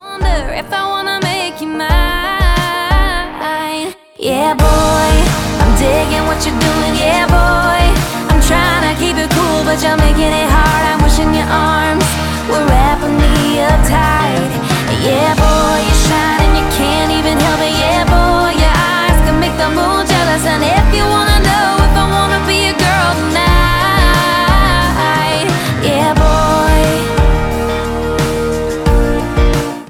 • Country
upbeat nature